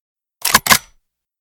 pump_new.ogg